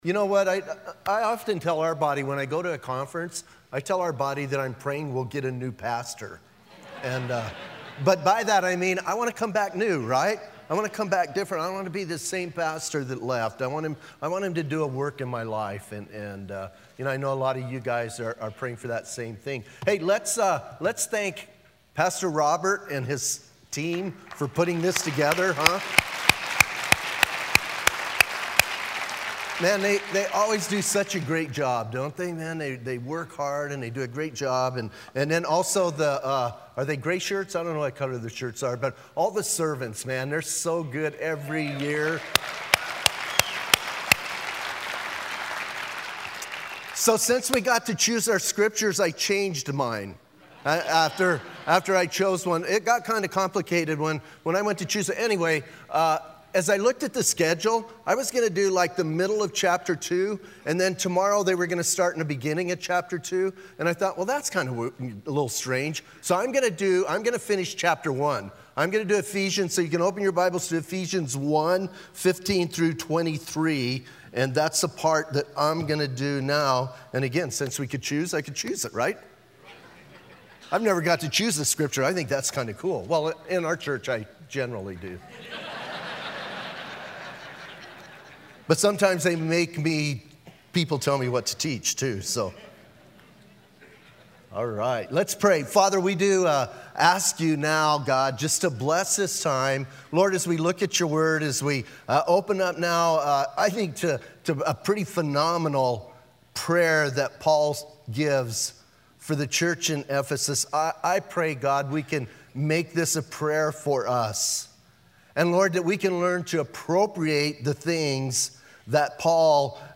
Southwest Pastors and Leaders Conference 2016